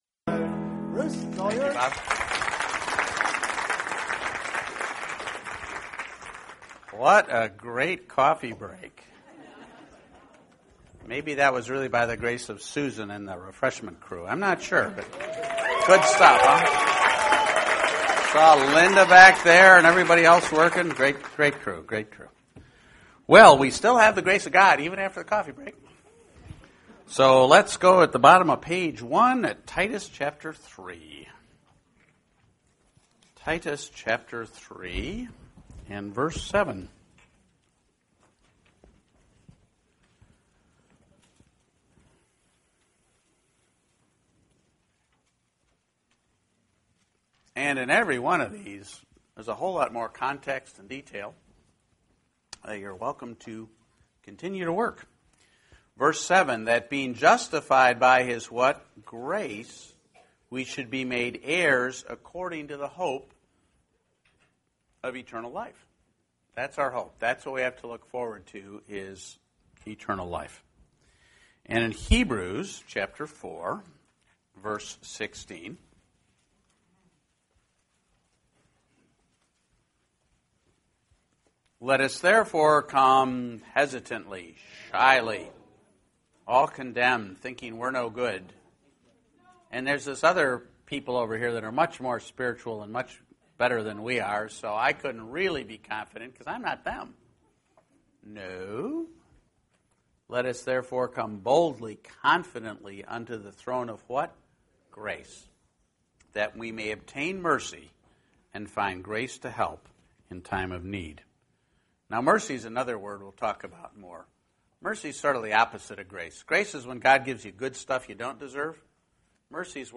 The Age of Grace The Age of Grace class looks at the wonderful blessings available to all Christian believers by God’s grace in this day and time – in comparison to the Old Testament administrations. We will also explore the practical application of what we have received as a result of the accomplished work of Jesus Christ.